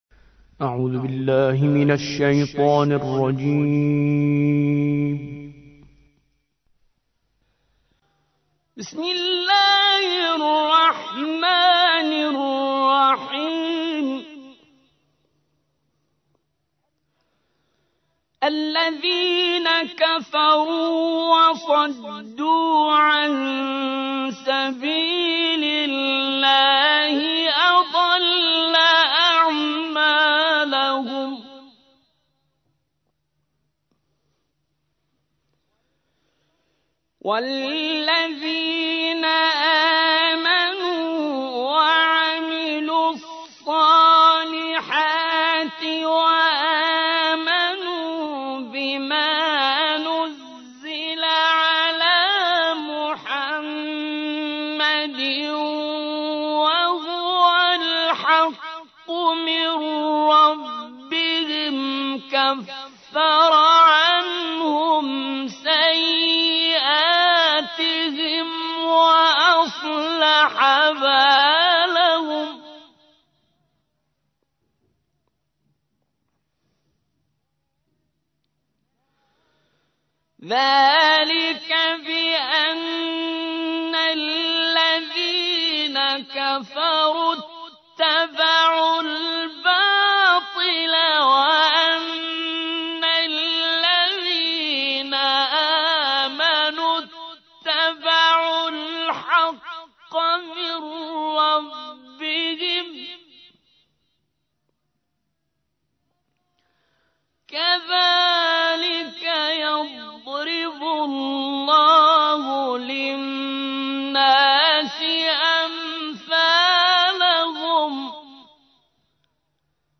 47. سورة محمد / القارئ